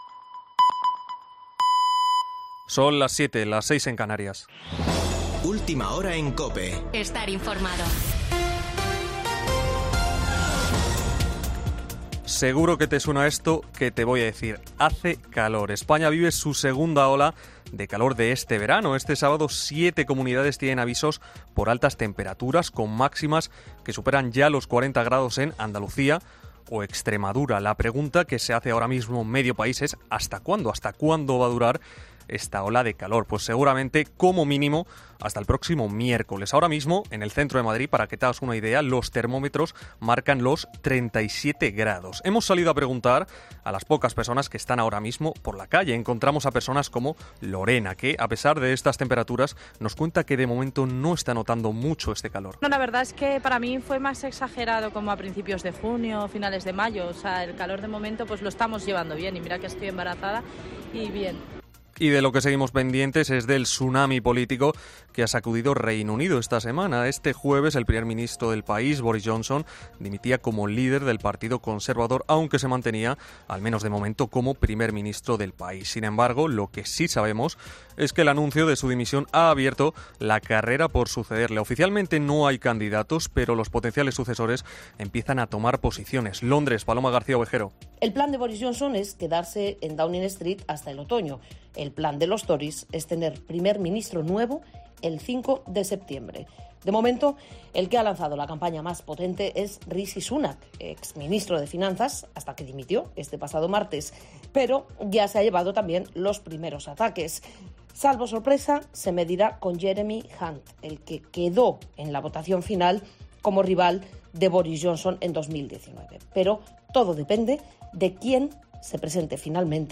AUDIO: Boletín de noticias de COPE del 9 de julio de 2022 a las 19.00 horas